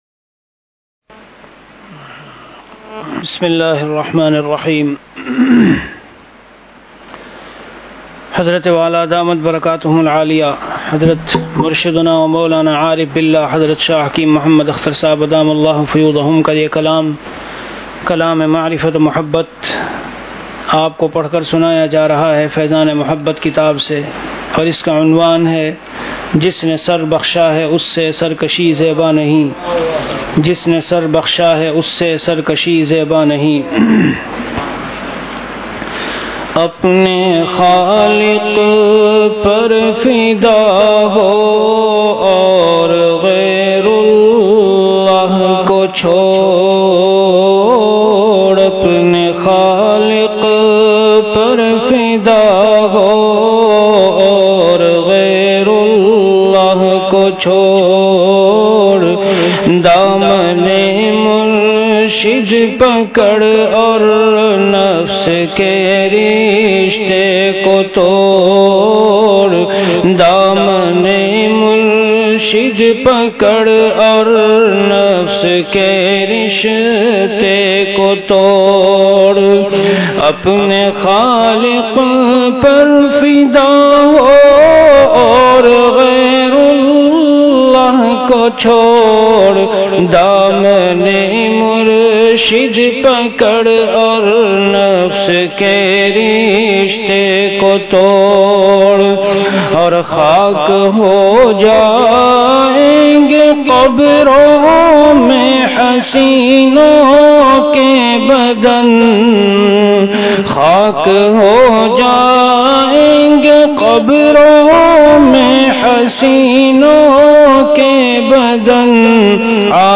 Bayanat · Khanqah Imdadia Ashrafia